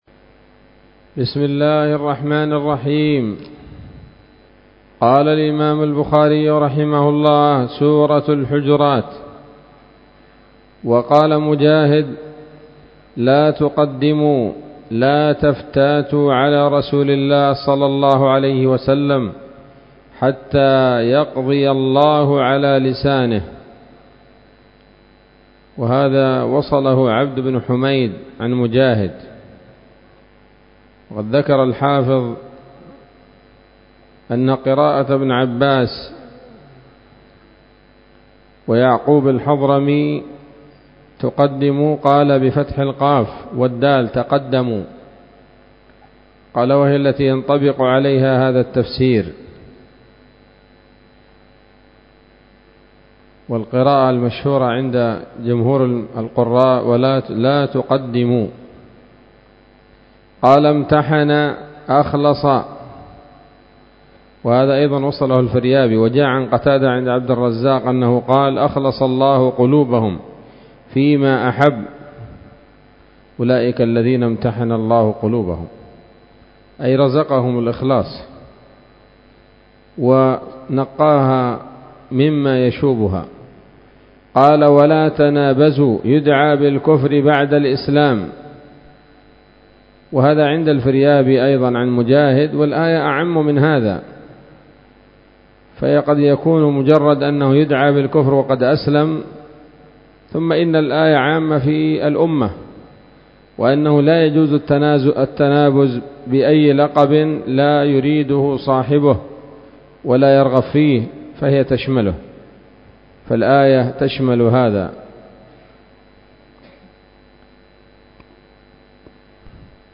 الدرس السادس والثلاثون بعد المائتين من كتاب التفسير من صحيح الإمام البخاري